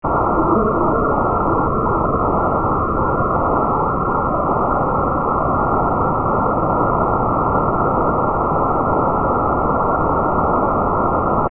Dichotic pitch is a pitch perception produced by two binaurally fused noise sequences, neither of which alone contain any cues to pitch.
Demo 1: Melody Lateralization
This sound file includes 10 presentations of a 4-note melodic signal at decreasing signal-to-background ratios (SBRs).
The background noise has an interaural time difference (ITD) of 0, so it should be perceived to be in the middle. The melodic signal ITD is randomly set to lead either the left or the right ear by 0.6 ms, so it will be perceived to be either to the left or to the right of the central background noise.